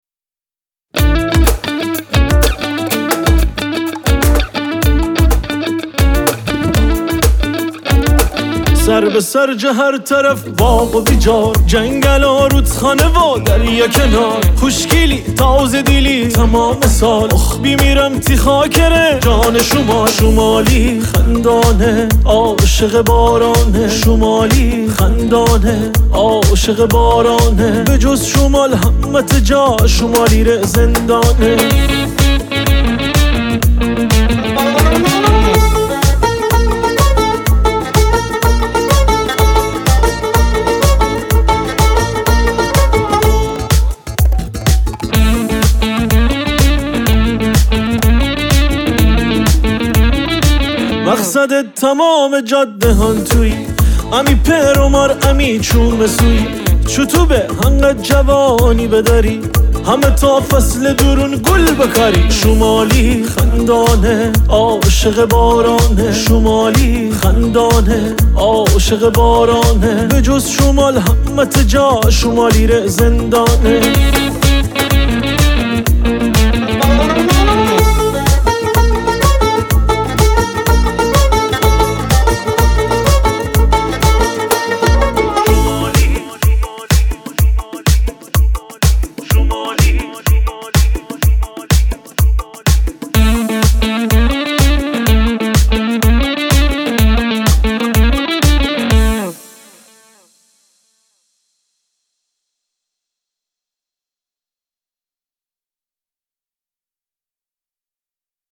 موزیک گیلانی شاد